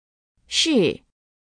shì